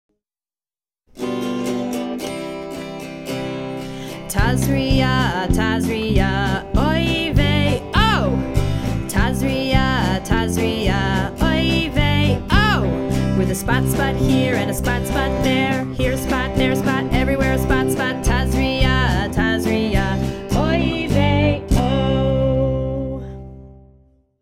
1) SONG: (tune: Old MacDonald Had a Farm)